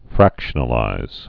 (frăkshə-nə-līz)